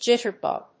音節jit･ter･bug発音記号・読み方ʤɪ́tərbʌ̀g
• / dʒíṭɚb`ʌg(米国英語)